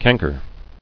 [can·ker]